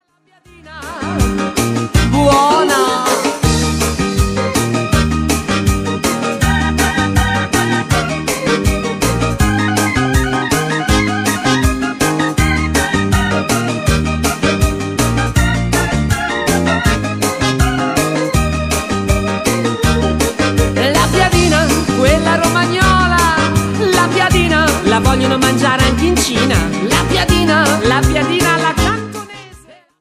TWIST  (2.43)